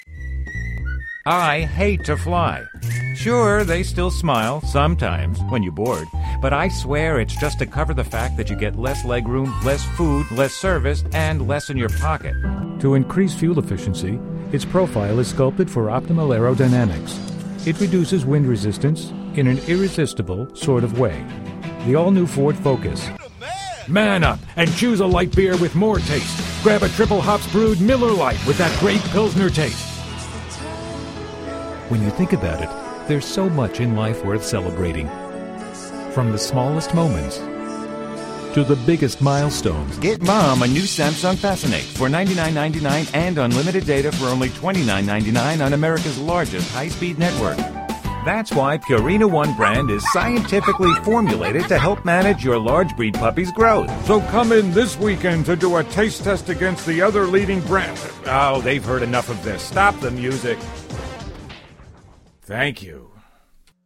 Demo
Mature Adult, Adult Has Own Studio NYC voice talent and TV audio engineer.
Location: Plainview, NY, USA Languages: english 123 Accents: standard us Voice Filters: VOICEOVER GENRE commercial NARRATION FILTERS authoritative character friendly trustworthy warm